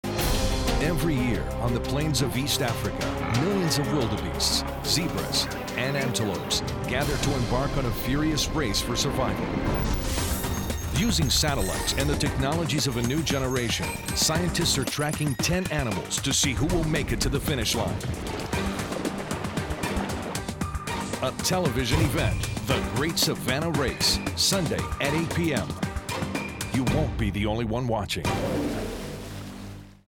Masculino
Inglês - América do Norte